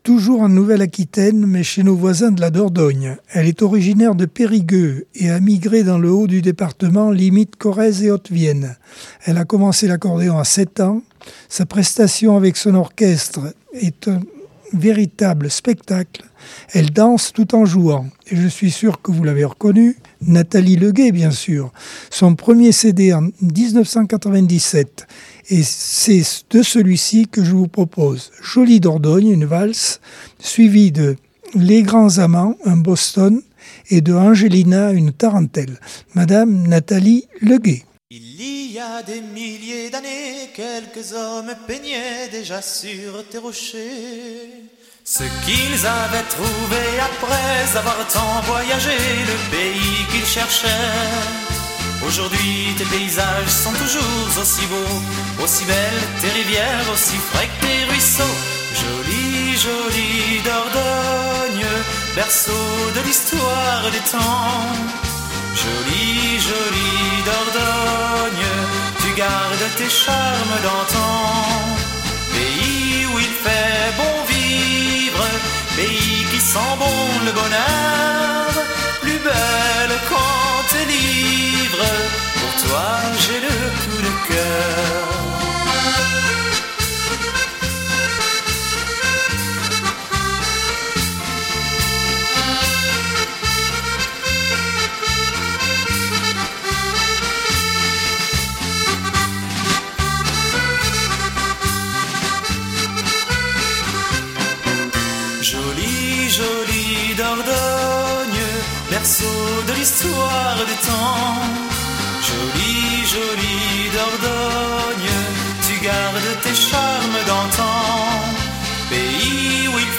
Accordeon 2024 sem 50 bloc 2 par Accordeon 2024 sem 50 bloc 2.